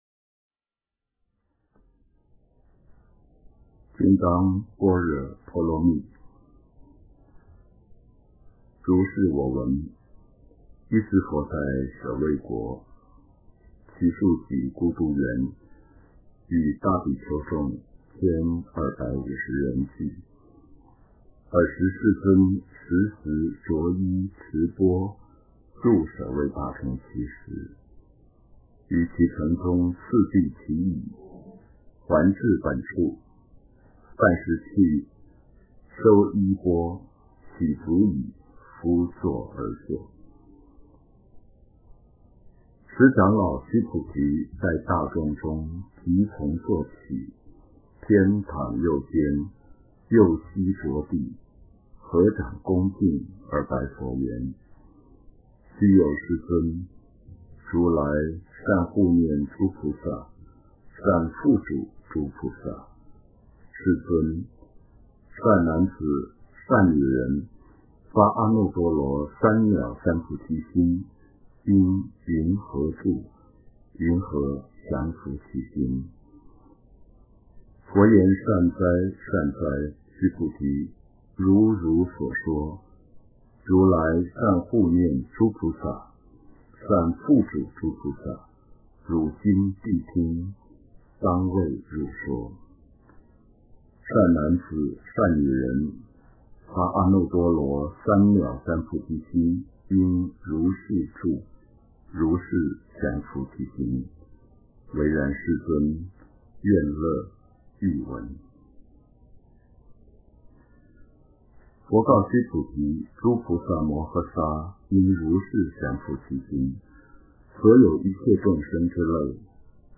金刚经 诵经 金刚经--蒋勋 点我： 标签: 佛音 诵经 佛教音乐 返回列表 上一篇： 《华严经》47卷 下一篇： 《华严经》51卷 相关文章 大自然音乐--未知 大自然音乐--未知...